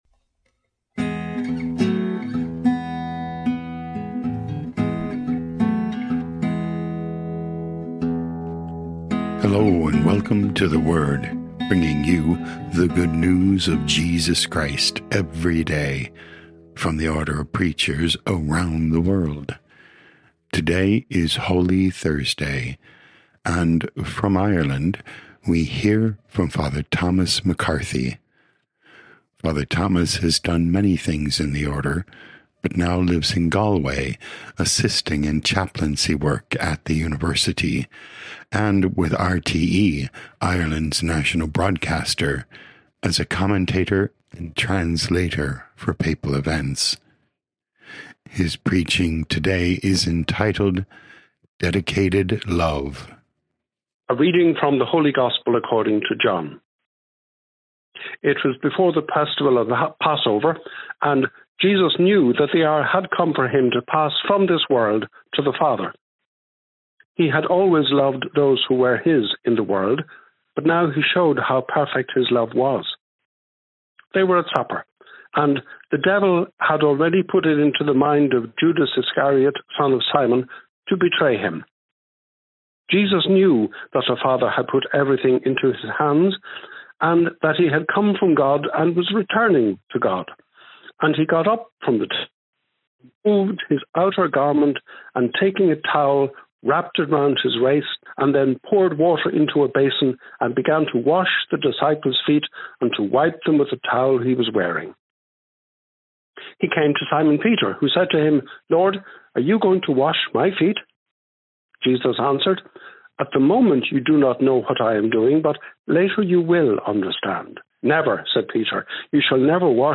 17 Apr 2025 Dedicated Love Podcast: Play in new window | Download For 17 April 2025, Holy Thursday, based on John 13:1-15, sent in from Galway, Ireland.
Preaching